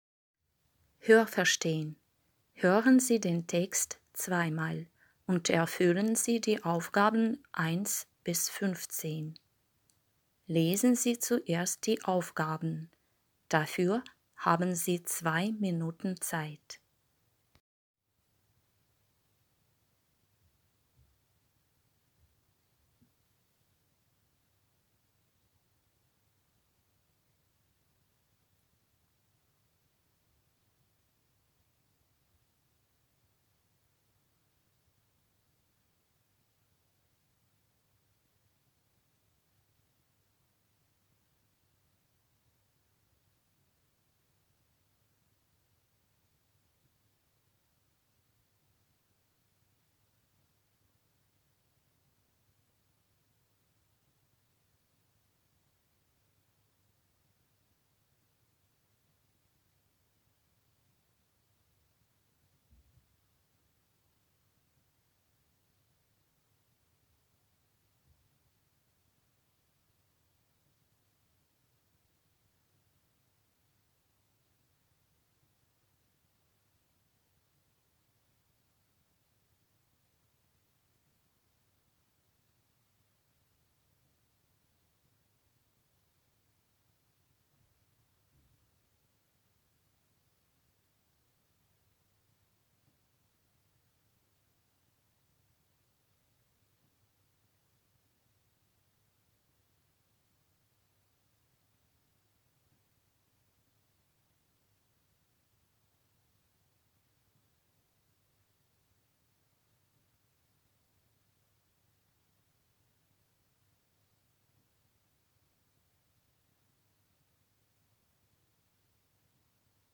21_Аудирование_9-11_классы.mp3